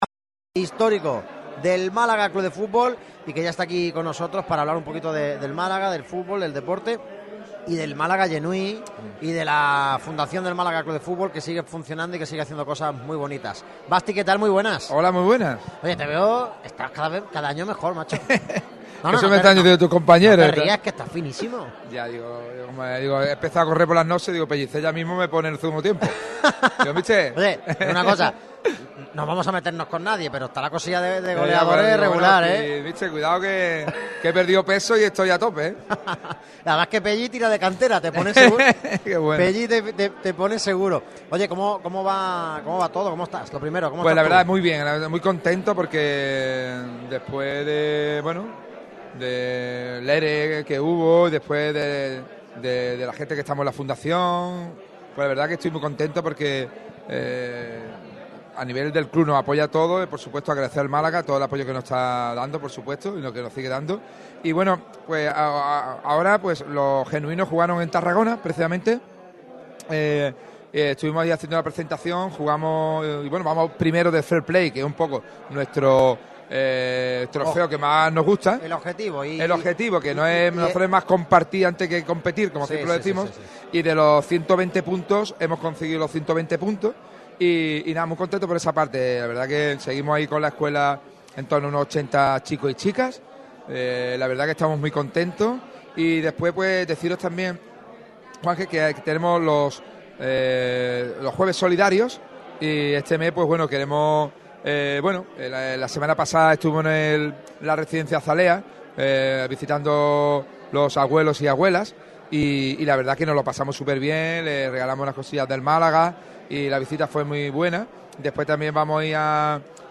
Este miércoles ha tenido lugar el encuentro navideño entre la prensa y los dirigentes y empleados del club en las instalaciones de La Rosaleda.